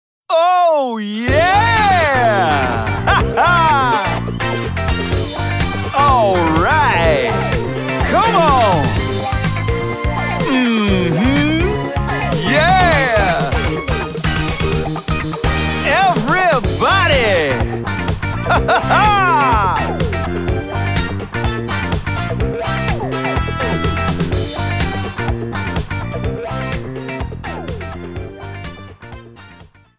Clipped to 30 seconds and applied fade-out